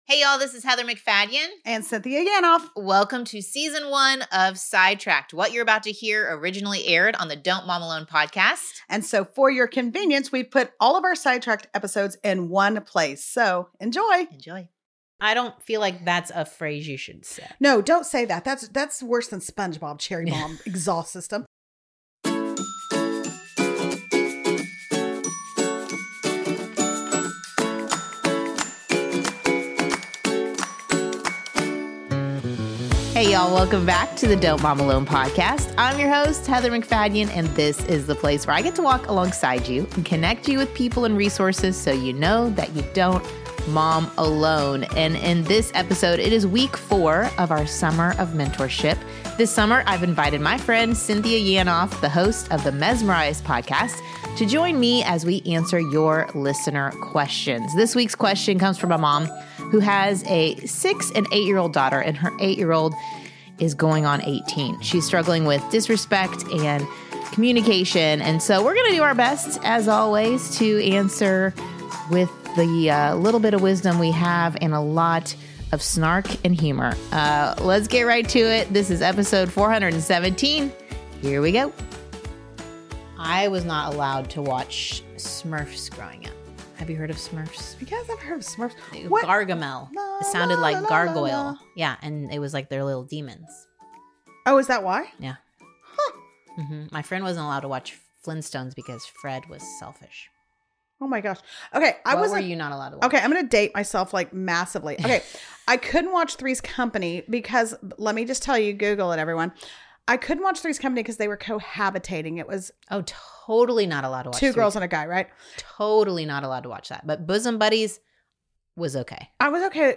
We’ll answer some listener questions, share our experiences, and laugh a lot.